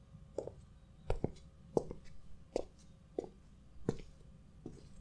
bruit de pas quand on s'avance dans le couloir